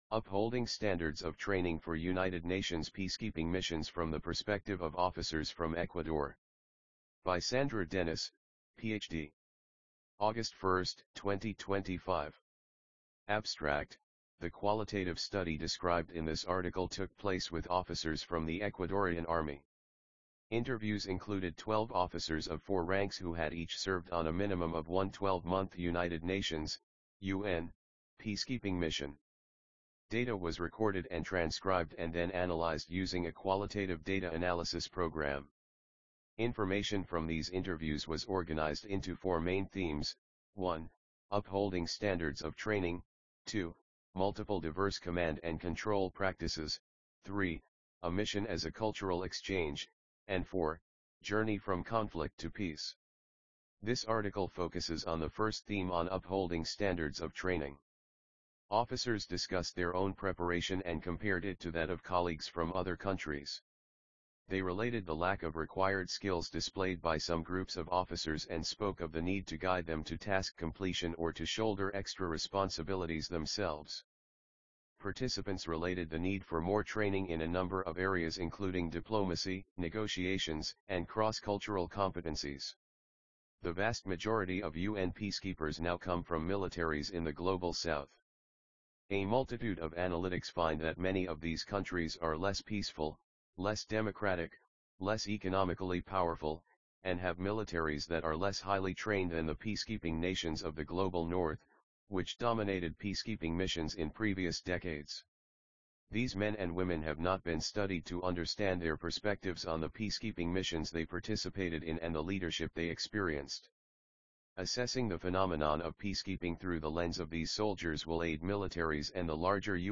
Upholding Standards of Training for UN Peacekeeping Missions_AUDIOBOOK.mp3